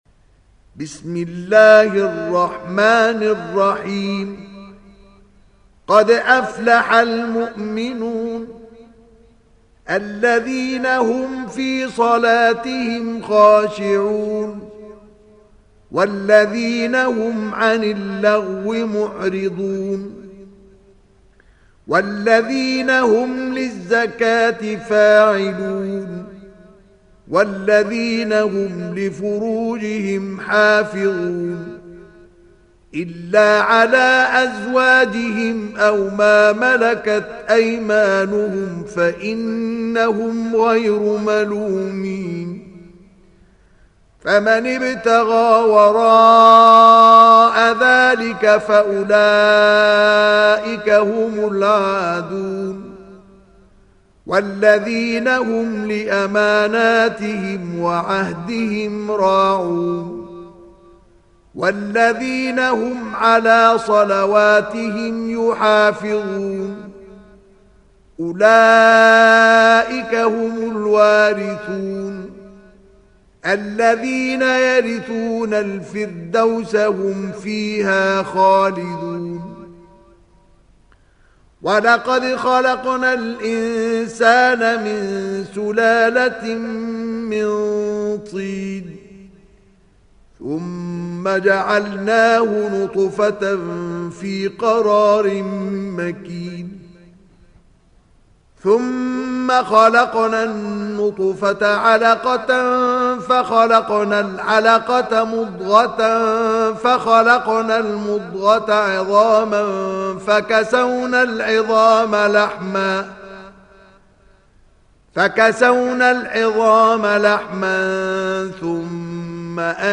دانلود سوره المؤمنون mp3 مصطفى إسماعيل روایت حفص از عاصم, قرآن را دانلود کنید و گوش کن mp3 ، لینک مستقیم کامل